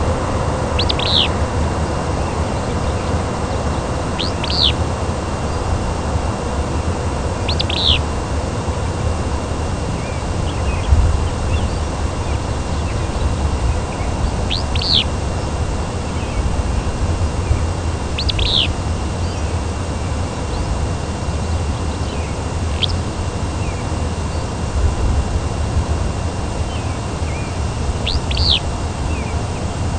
Willow Flycatcher
The Willow's "Zwee-oo" call may cause some confusion among observers who know the advertising songs of the two species, but don't know about this vocalization.